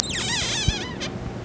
1 channel
squeaky.mp3